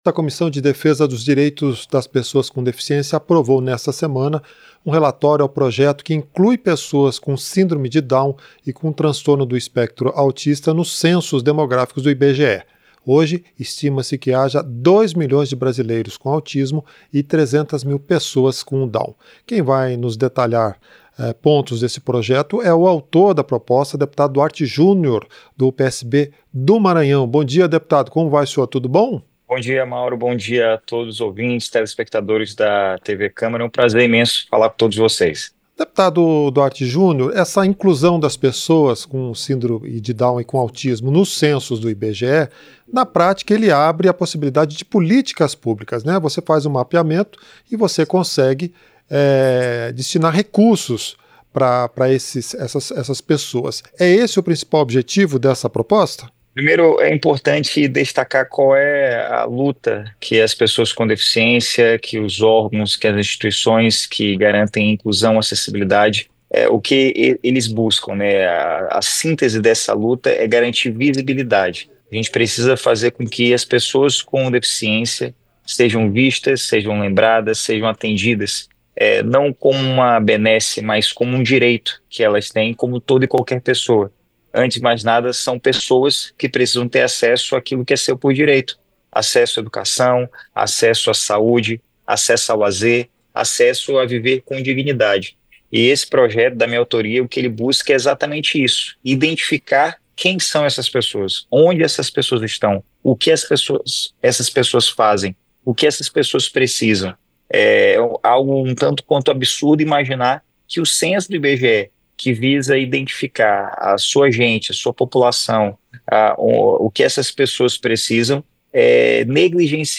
Entrevista - Dep. Duarte Jr (PSB-MA)